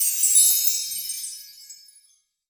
chimes_magical_bells_07.wav